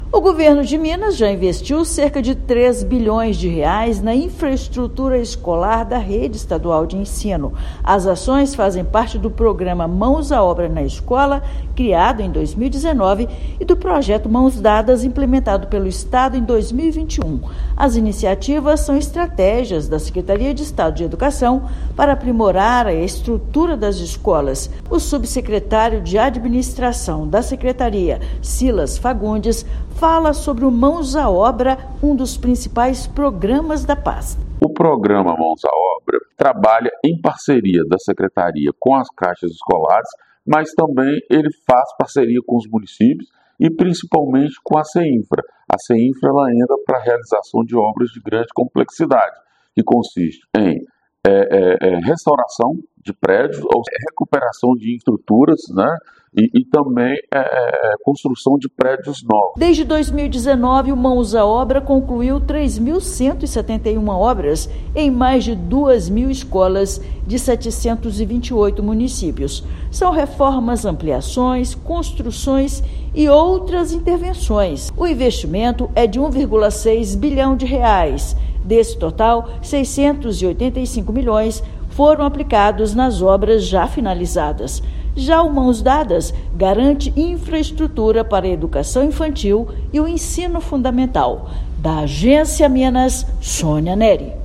Iniciativas como o Mãos à Obra na Escola e o Mãos Dadas impulsionam melhorias estruturais e fortalecem a educação na rede de ensino. Ouça matéria de rádio.